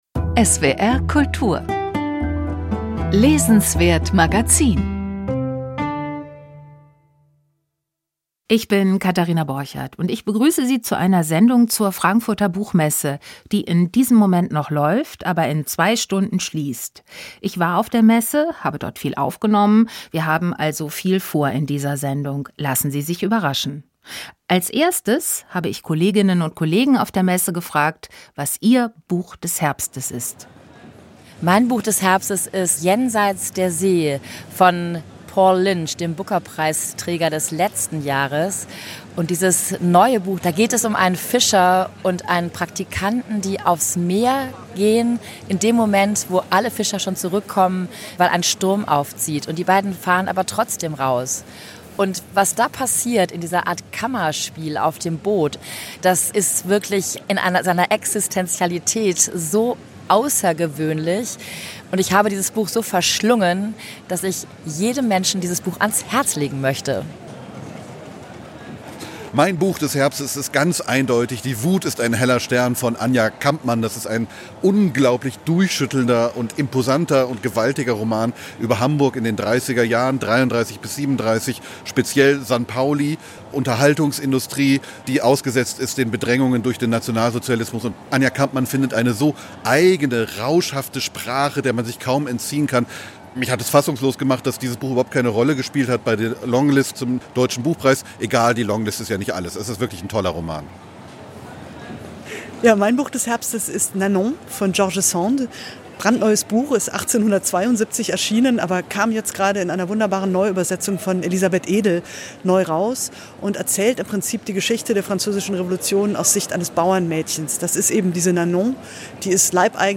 Was war wichtig auf der Frankfurter Buchmesse? Wir besprechen Themen und Trends. Außerdem haben wir Krimiautor Friedrich Ani zu Gast. Und die Tagalog-Übersetzerin Annette Hug.